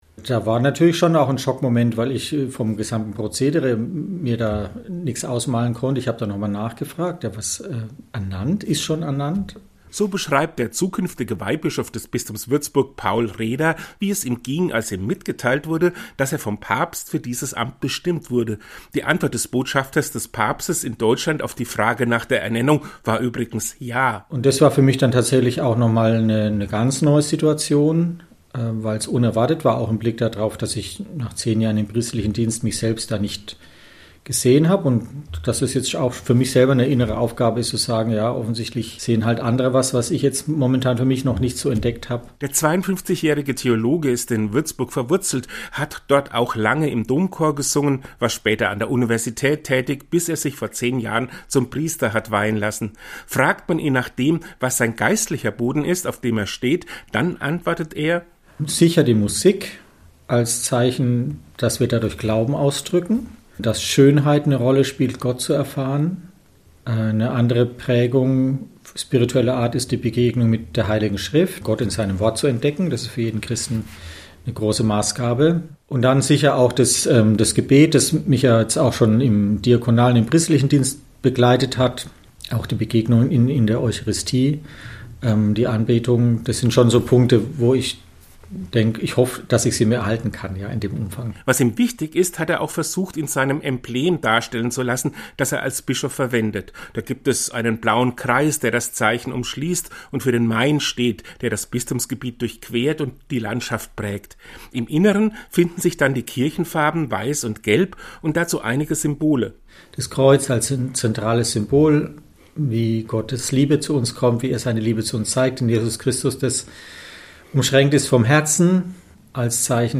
Paul Reder im Gespräch